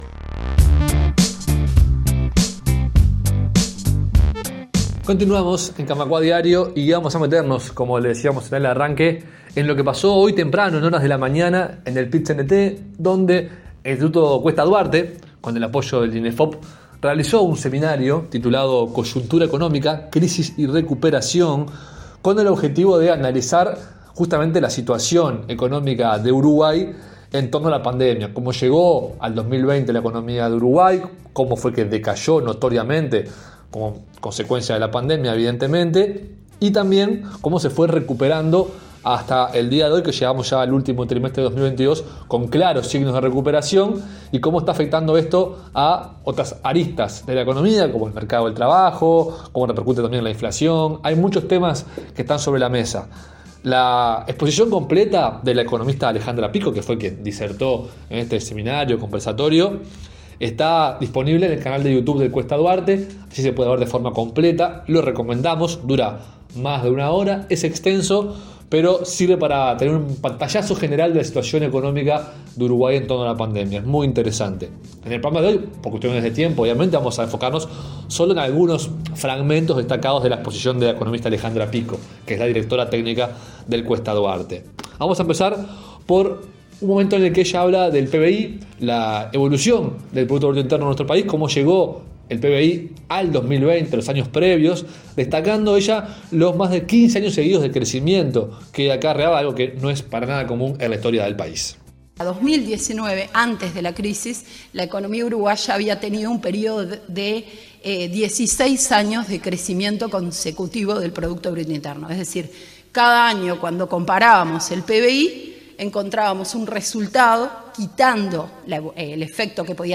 brindó este miércoles un seminario sobre la coyuntura económica de nuestro país, la crisis ocasionada por la pandemia y cómo se está dando la recuperación.